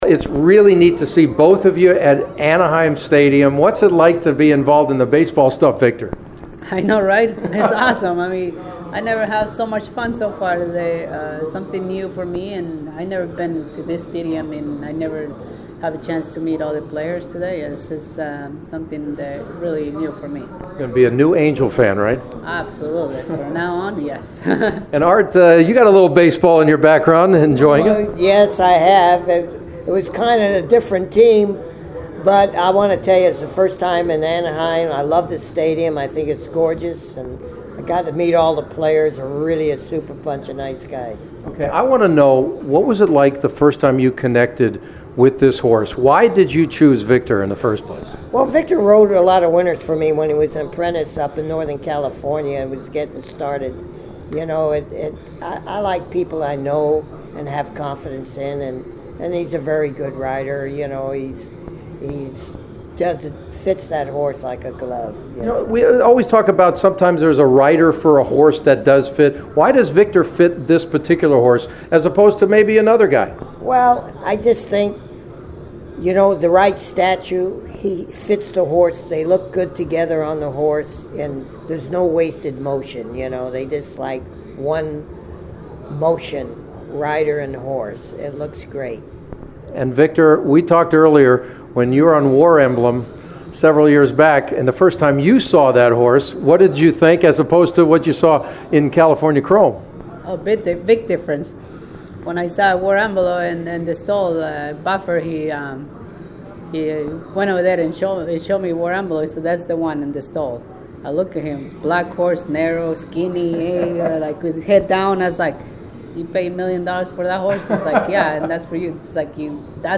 California Chrome is now just one leg away from horse racing’s first triple crown since Affirmed did in the 1978. And tonight I had the pleasure to sit down with his trainer Art Sherman and jockey Victor Espinoza before they threw out the ceremonial first pitch in Anaheim with the Angels and Houston Astros set to do battle.